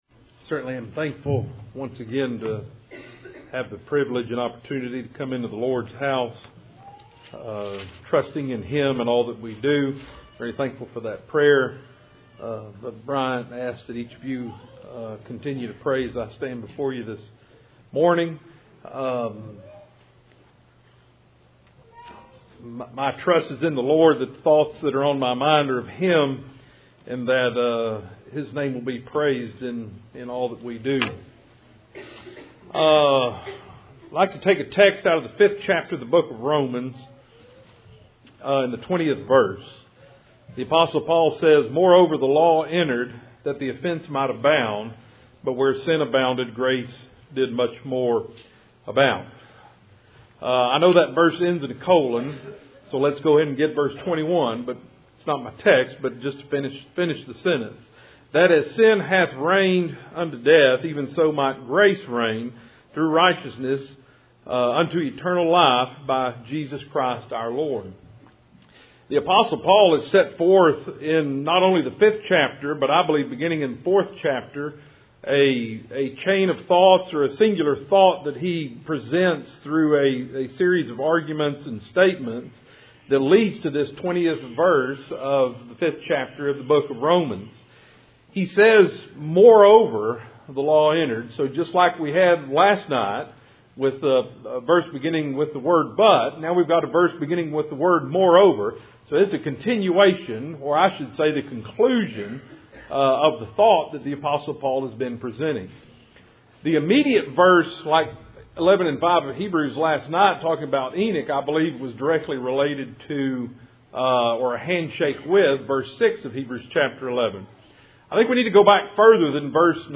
Passage: Romans 5:20 Service Type: Cool Springs PBC March Annual Meeting %todo_render% « Faith